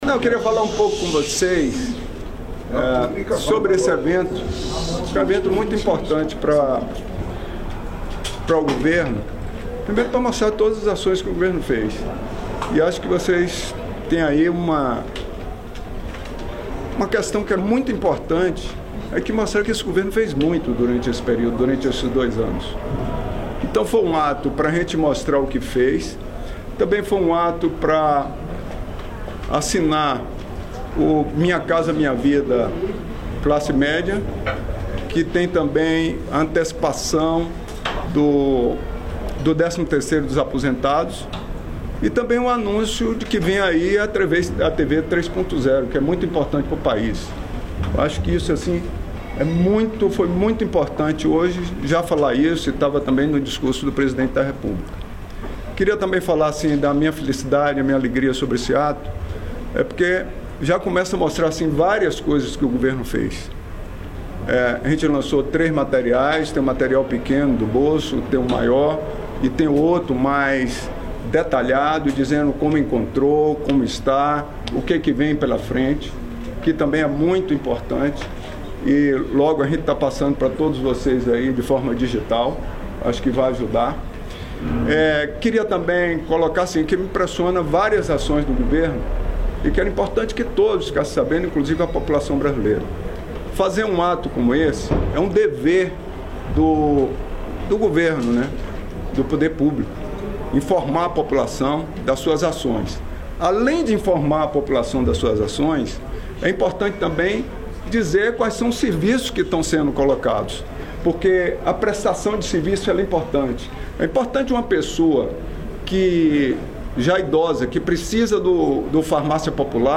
Íntegra da entrevista do presidente da República em exercício, Geraldo Alckmin, durante visita à nova fábrica da Fresenius Medical Care, nesta sexta-feira (28), em Jaguariúna (SP).